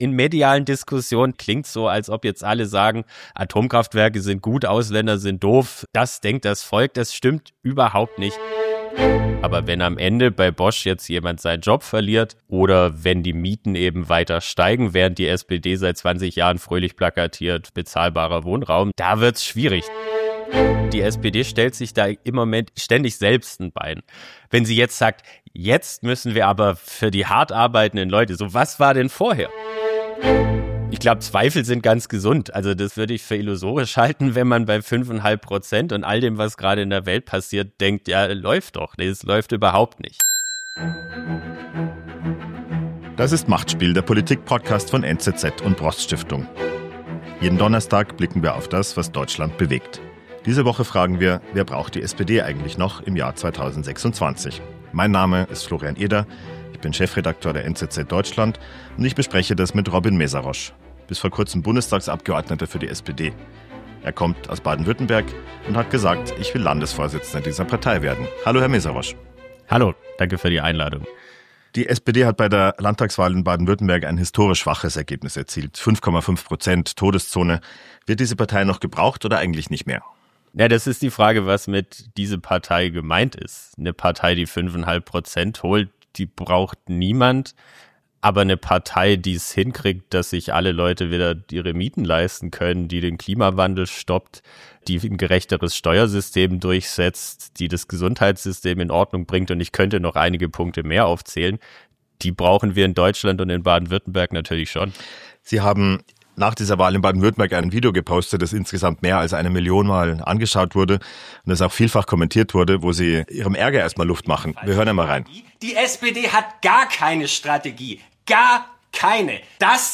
Der Ex-Bundestagsabgeordnete Robin Mesarosch analysiert die strukturellen Probleme der SPD und spart nicht mit Kritik. Er spricht über fehlende Strategien, Glaubwürdigkeitsverluste und warum viele Wähler zur AfD abwandern.